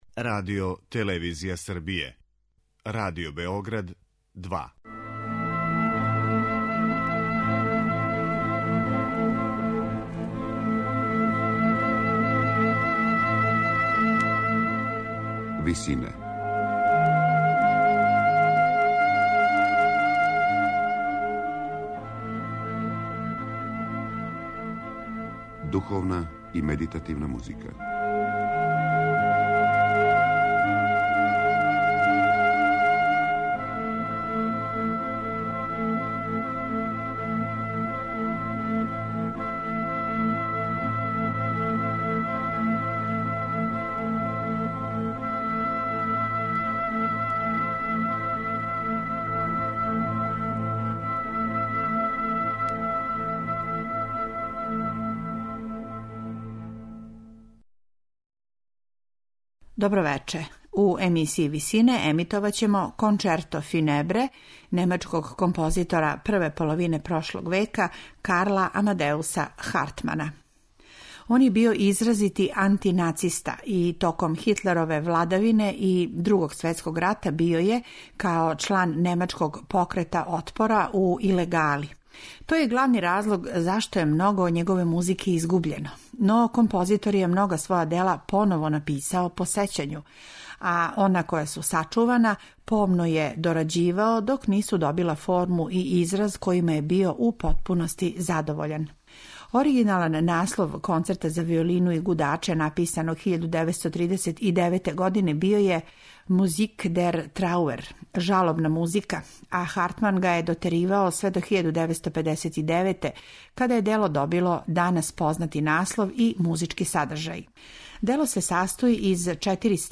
"погребни концерт"